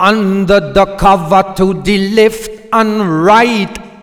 OLDRAGGA5 -L.wav